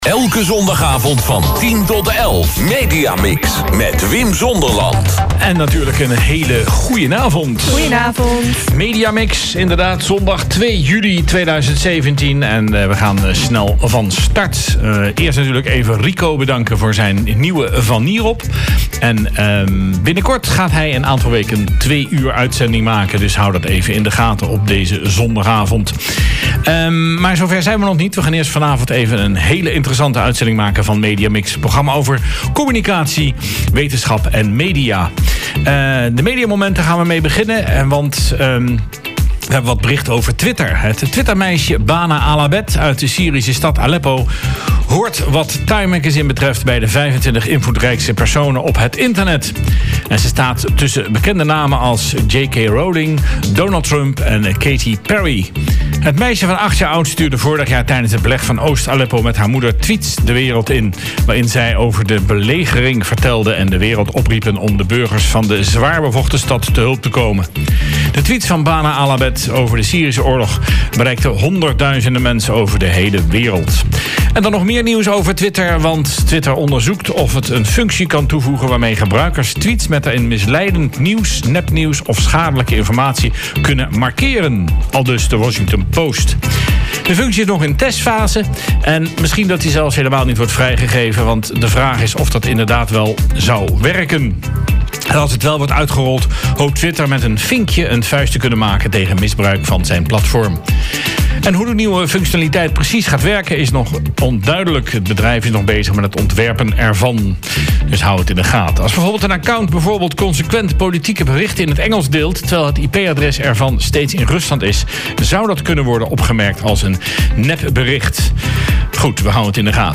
In het tweede deel zit het interview over de open coax.